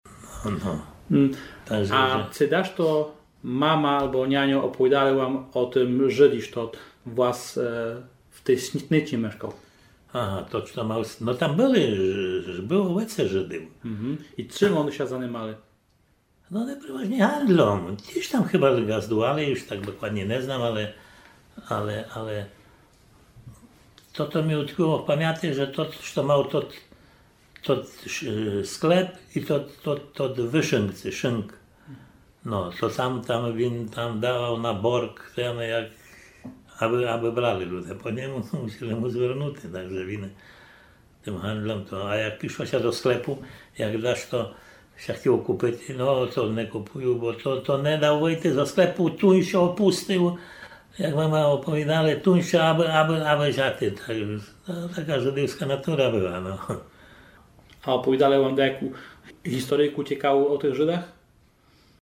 Хоц то моі реляциі нагрываны сут на Заході, то заєдно споминаме на Лемковину, прадідівску землю вшыткых нашых люди, де бы они не одышли, і де бы не были розметаны по шырокым світі.